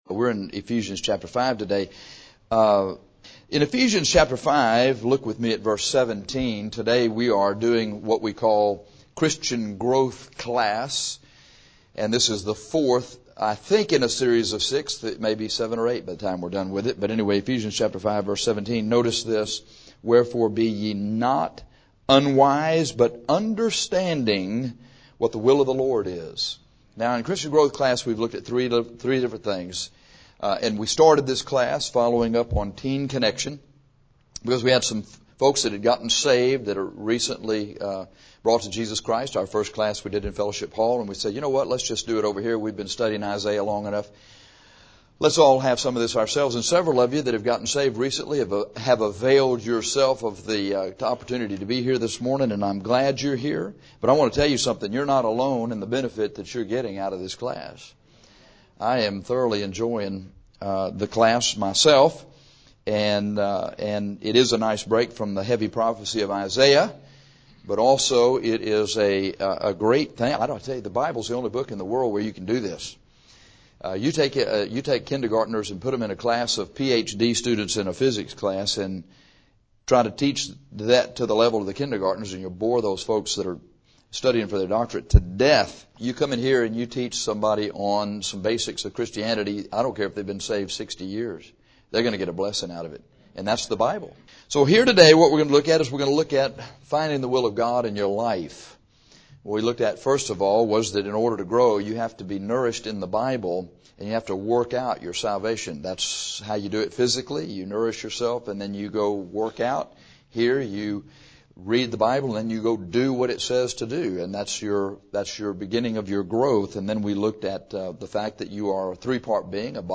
Christian Growth IV is the fourth in a series of lessons on Christian growth. This lesson is on finding the stated and the specific will of God.